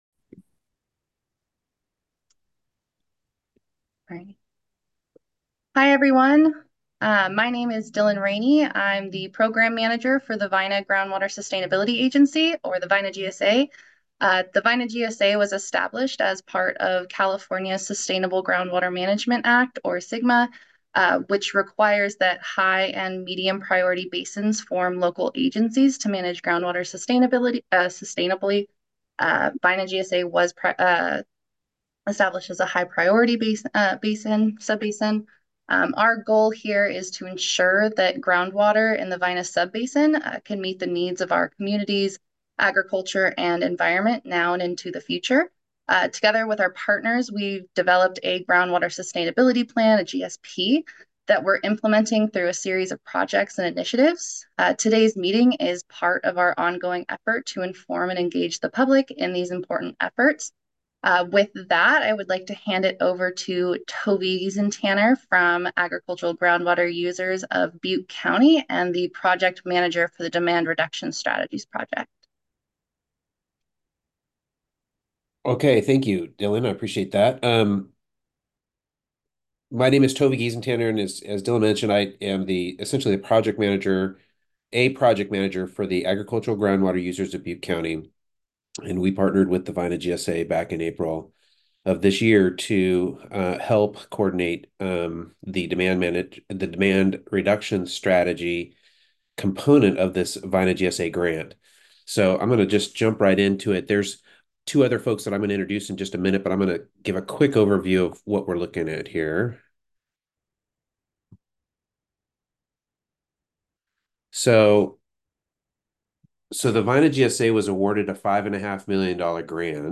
Demand+Reduction+Strategies+Webinar+Audio.m4a